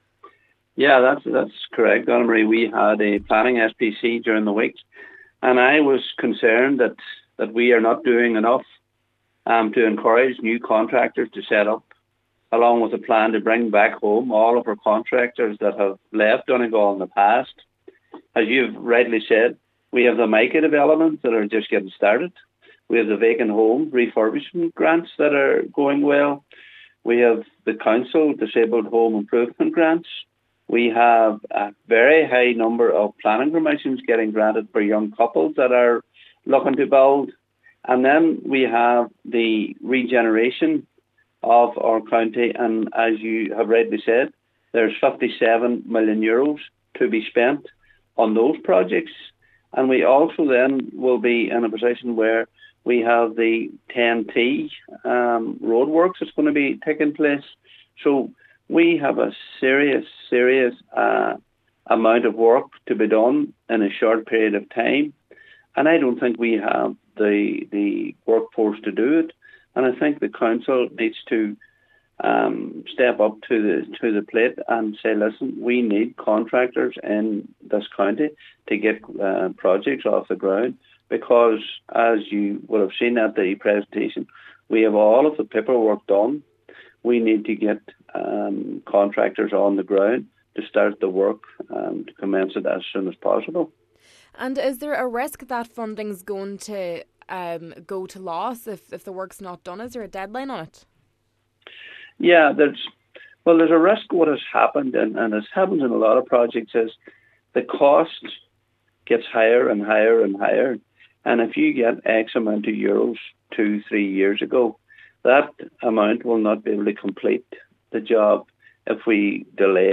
Cllr Canning says many contractors have left the county: